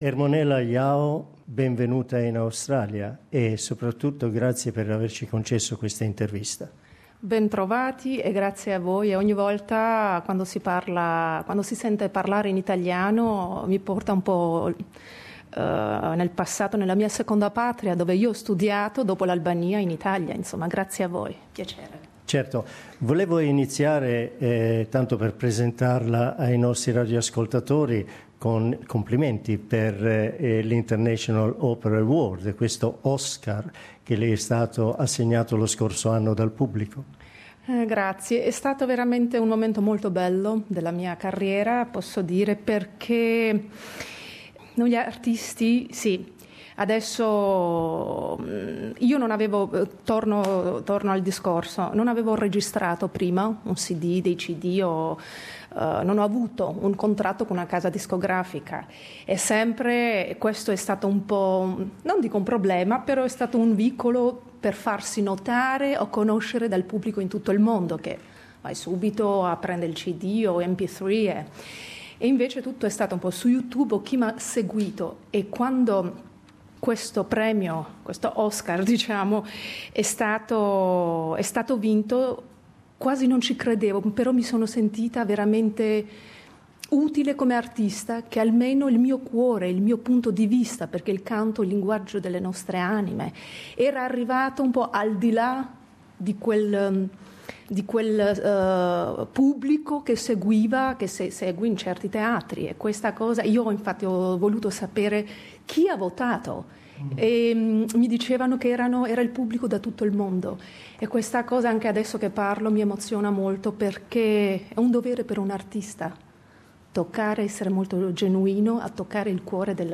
La nostra intervista.